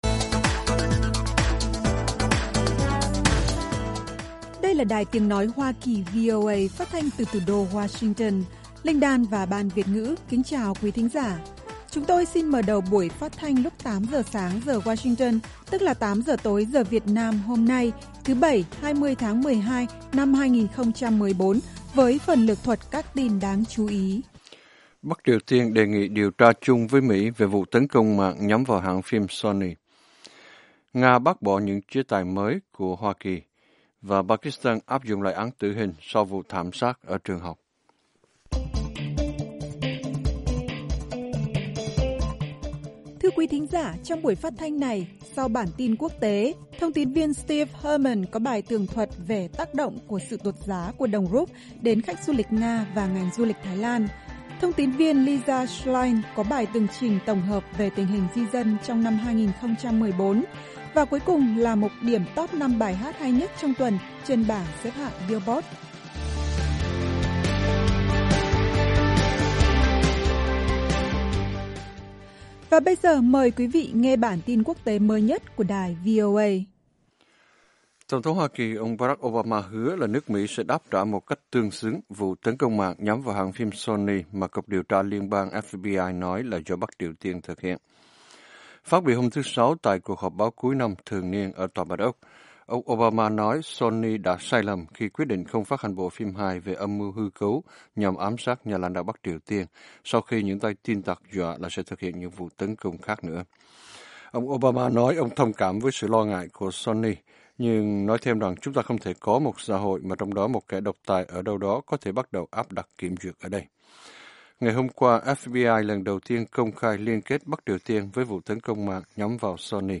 Tin tức mới nhất và các chuyên mục đặc biệt về Việt Nam và Thế giới. Các bài phỏng vấn, tường trình của các phóng viên ban Việt ngữ về các vấn đề liên quan đến Việt Nam và quốc tế.